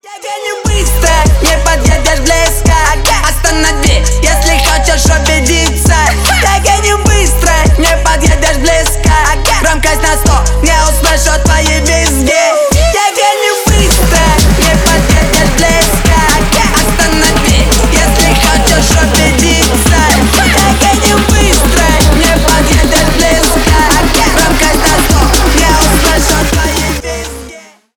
Ремикс # Рэп и Хип Хоп